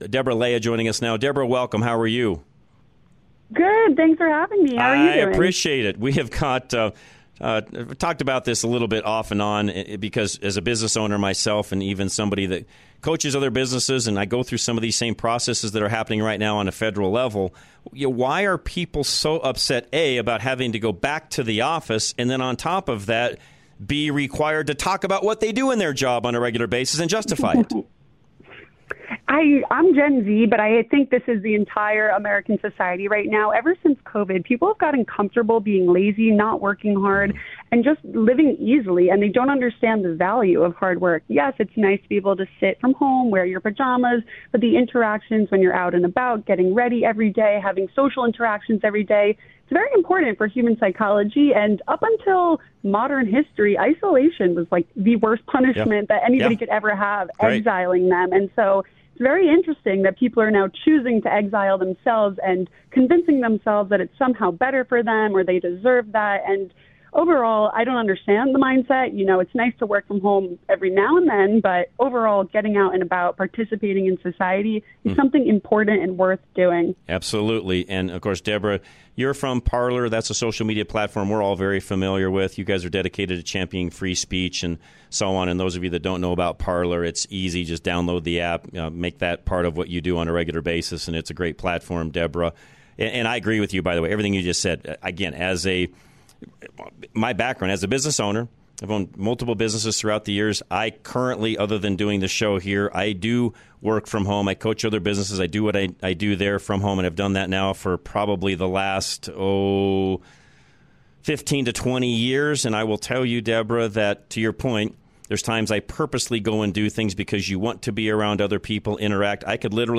Rush To Reason - Interviews